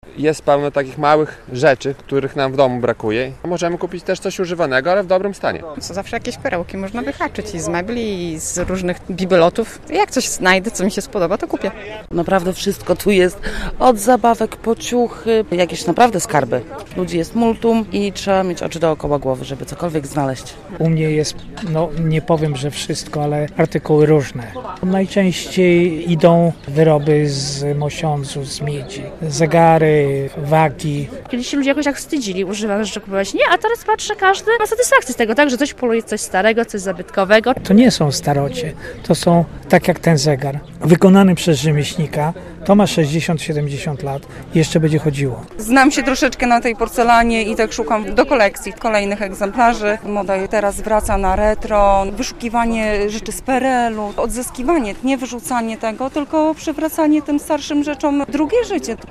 Targi staroci przyciągają tych, którzy szukają starych sprzętów, części czy bibelotów - relacja